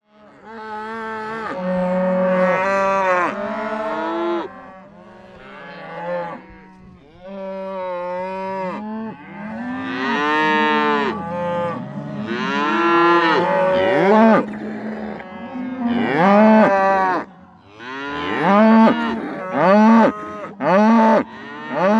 Звуки коров и телят в стаде